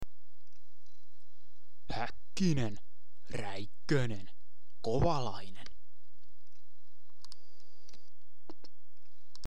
finnish f1 drivers the correct pronounciation for dummies.mp3
There you go, might be little bit on the quiet side, recorded it with my old laptop's integrated mic.